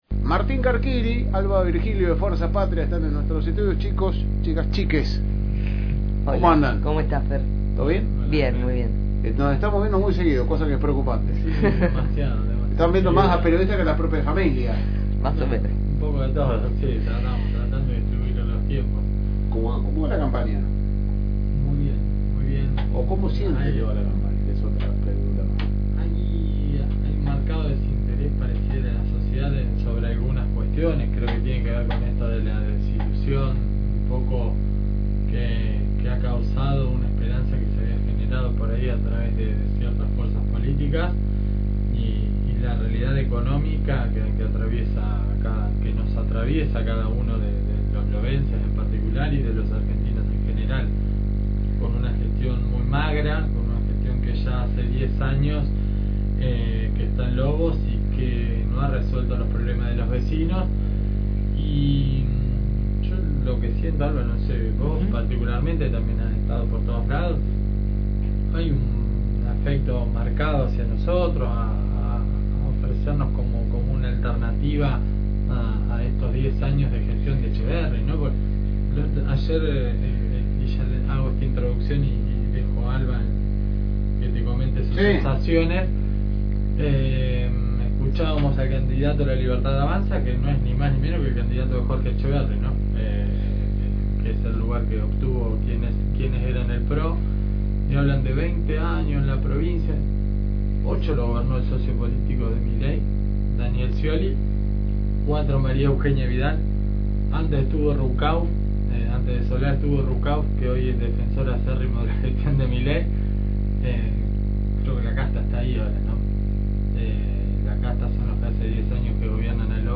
Charla extensa en la mañana de la radio donde los candidatos nos contaban como viene la campaña y las actividades que van a tener durante la campaña hasta hasta el día de la elección.